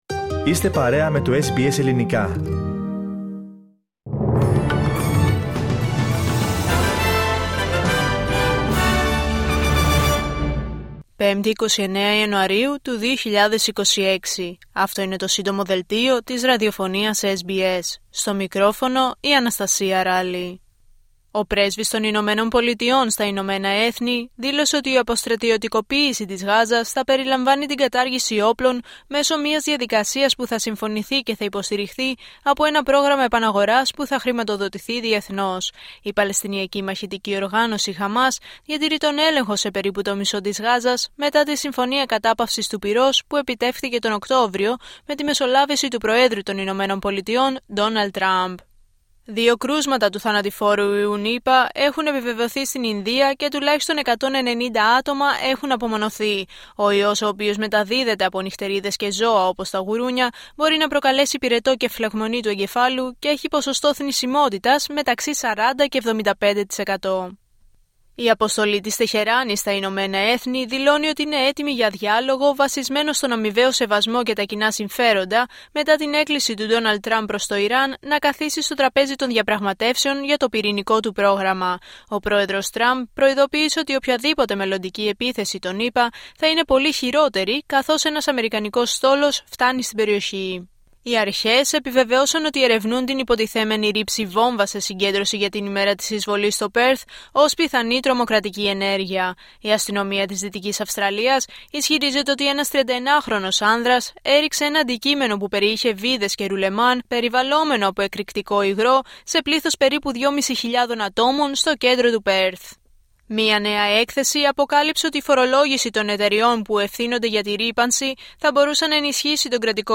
H επικαιρότητα έως αυτή την ώρα στην Αυστραλία, την Ελλάδα, την Κύπρο και τον κόσμο στο Σύντομο Δελτίο Ειδήσεων της Πέμπτης 29 Ιανουαρίου 2026.